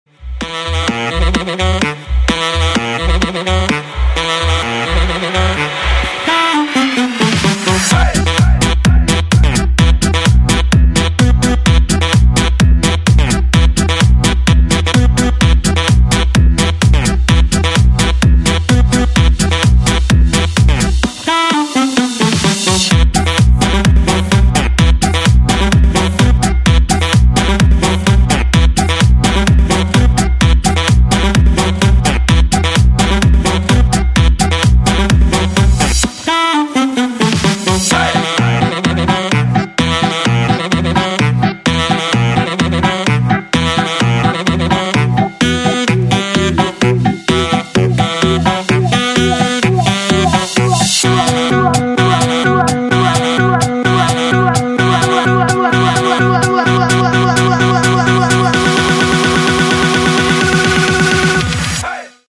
мужской голос
громкие
веселые
заводные
dance
EDM
Саксофон
house